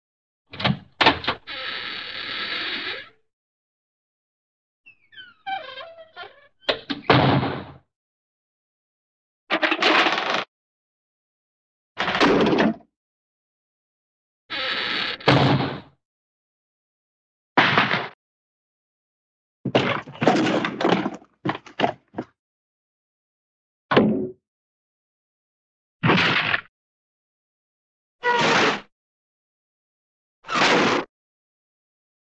35 1 Door, Wood Squeaky Open
Category: Sound FX   Right: Personal
Tags: Cartoon